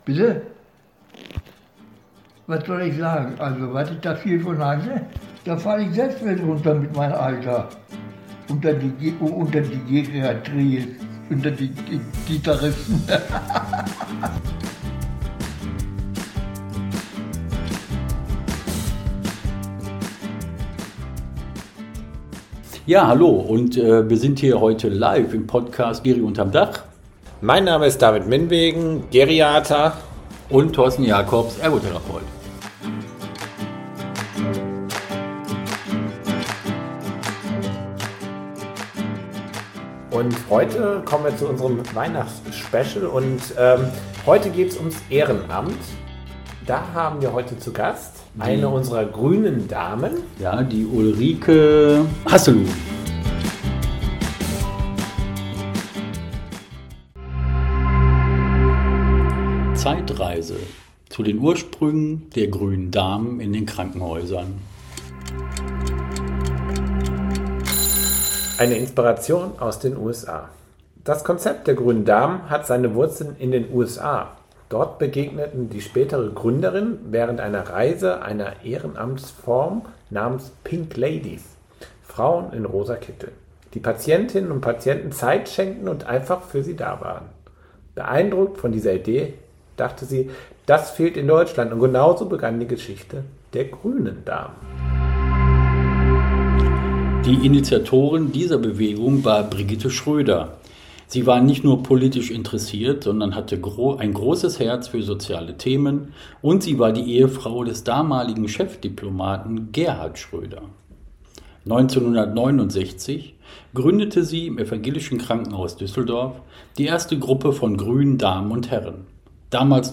Aufgenommen wird der Podcast in der siebten Etage des Alfried Krupp Krankenhaus - also “unter'm Dach”.